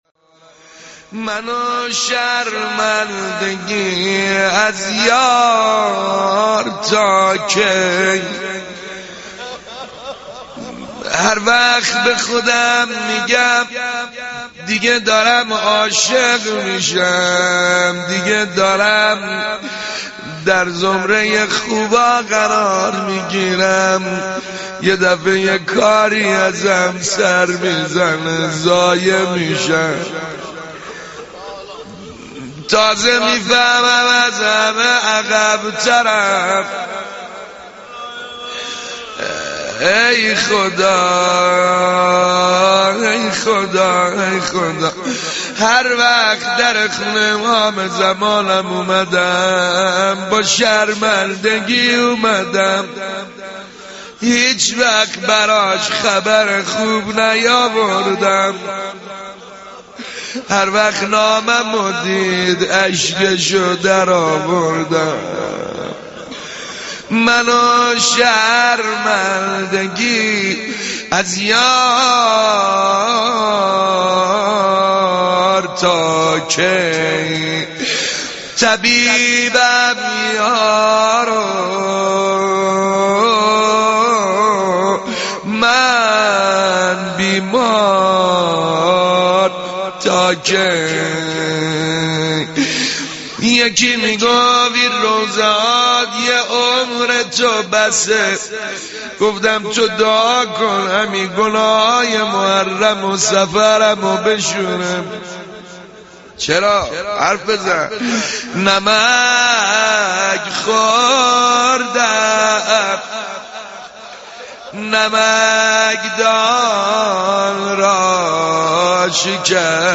گلچین مناجات با امام زمان